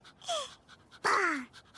Anime sound
evil laugh female voice laughter Anime sound effects